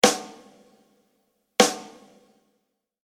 Drum-Tuning
Prägnanter, konkreter Sound mit brillanten Obertönen
Die Snareteppich-Ansprache soll schnell und direkt sein.
Der Snareteppich wird ebenfalls recht straff gespannt, sodass er bei einem Anschlag satt und ohne weiteres Nachrascheln gegen das Resonanzfell schlägt.
Die so gestimmte Snaredrum wird nun noch entsprechend leicht gedämpft.
dt_snr01.mp3